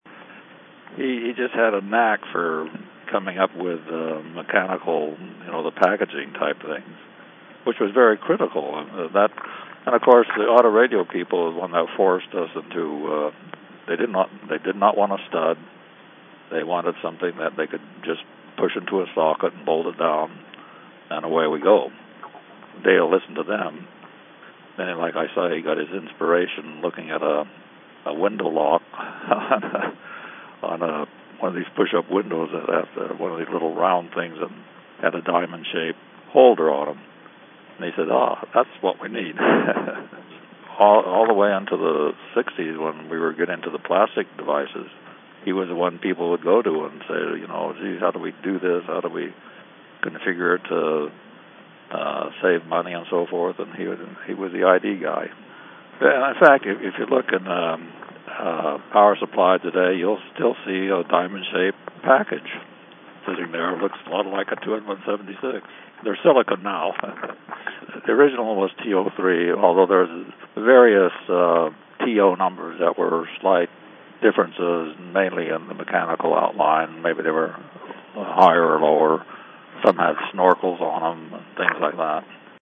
from a 2008 Interview with